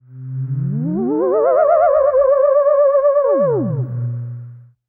Theremin_Swoop_14.wav